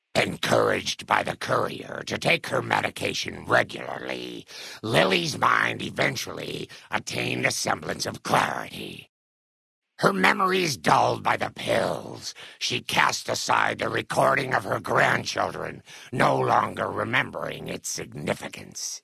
Category:Fallout: New Vegas endgame narrations Du kannst diese Datei nicht überschreiben.